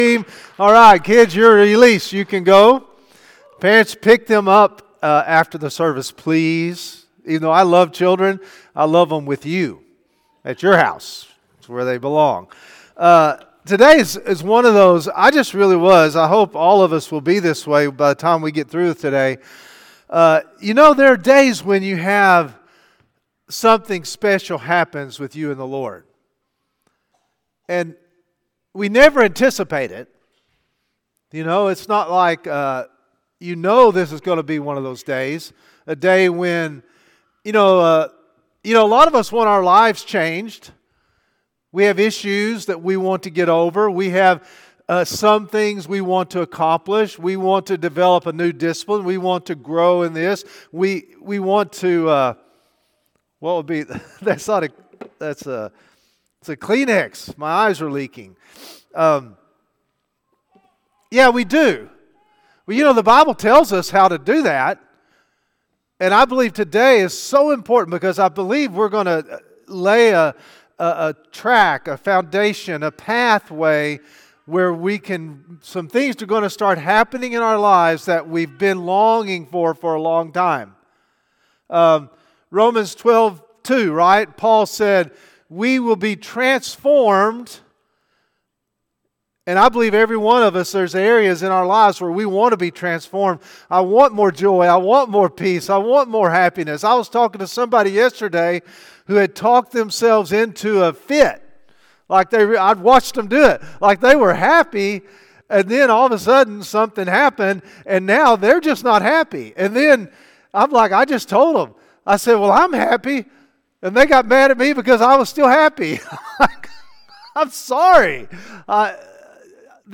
1 Morning Service